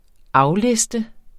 Udtale [ ˈɑwˌlesdə ]